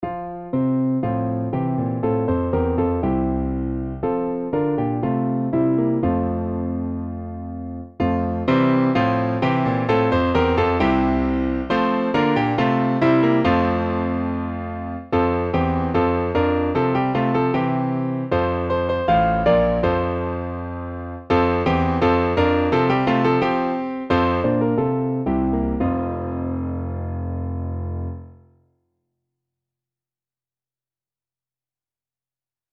ベル、ブザー、アラーム